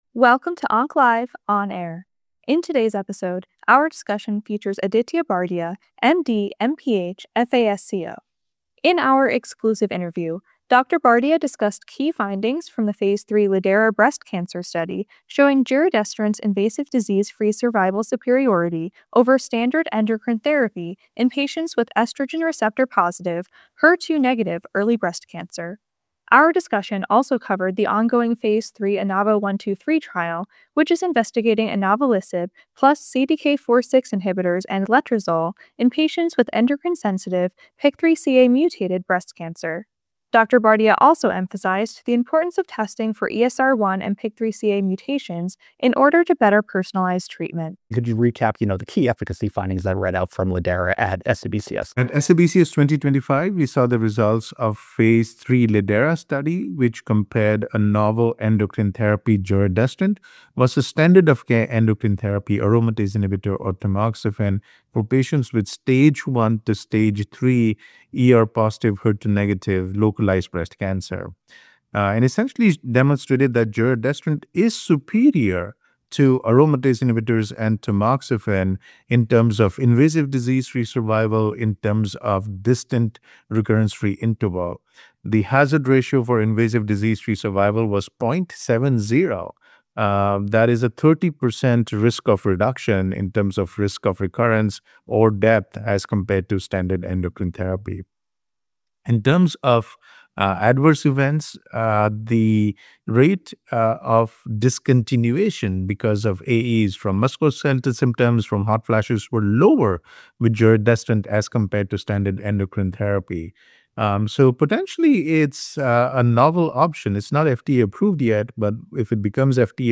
In our exclusive interview